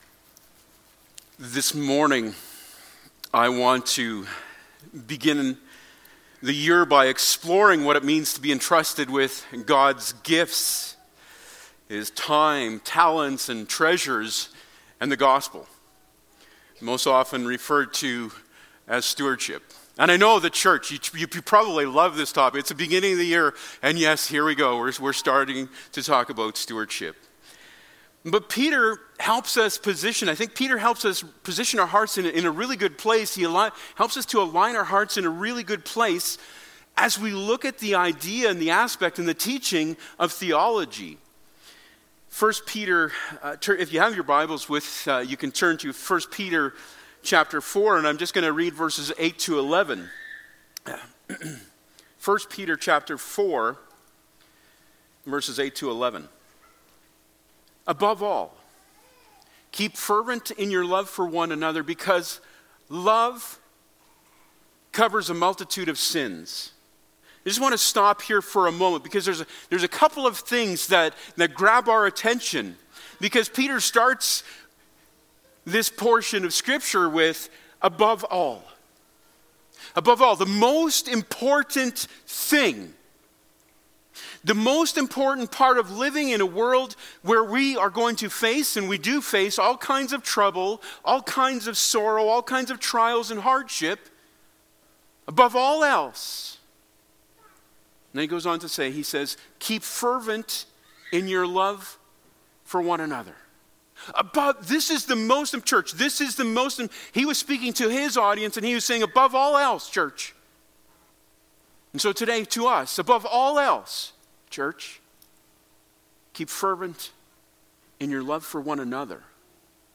Service Type: Sunday Morning Topics: Stewardship